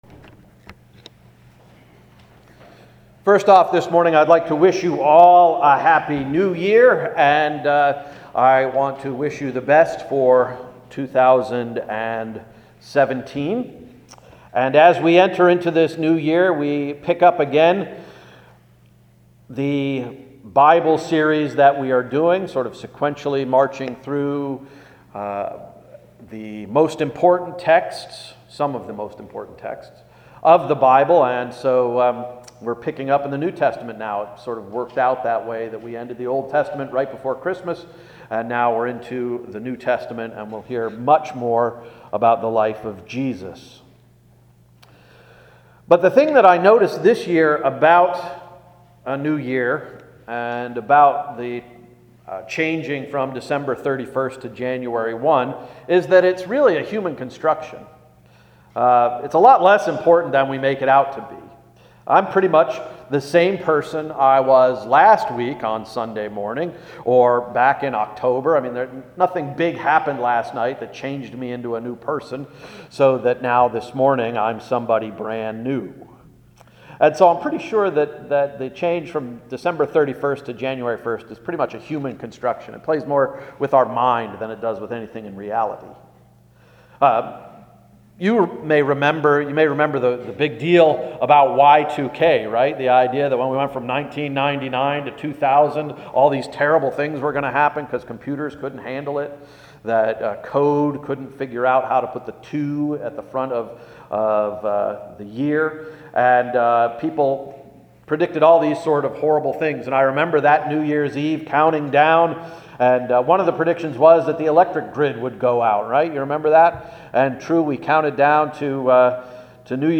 January 1, 2017 Sermon — “And so it begins” – Emmanuel Reformed Church of the United Church of Christ
Welcome to Hill's Church Export, PA